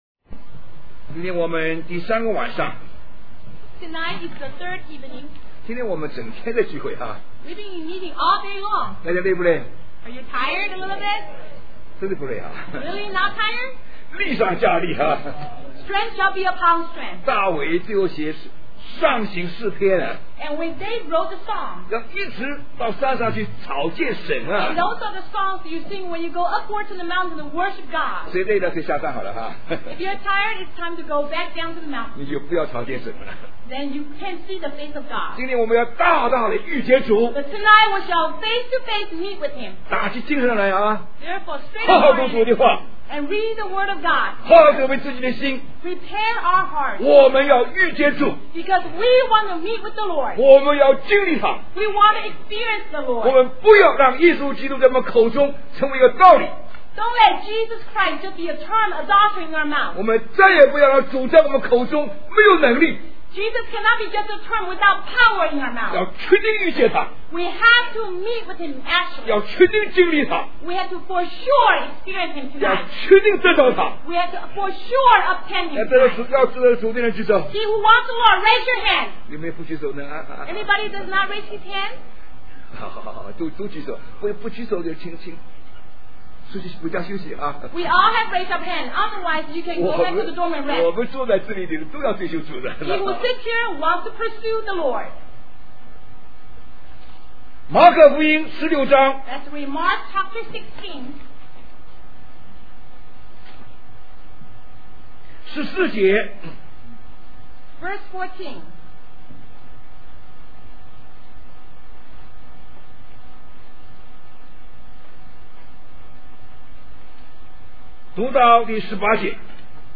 In this sermon, the speaker emphasizes the importance of experiencing and obtaining a personal relationship with Jesus Christ. He encourages the audience to not just use Jesus' name as a mere term, but to truly meet and encounter Him. The speaker urges everyone to raise their hands if they desire to pursue the Lord and experience Him.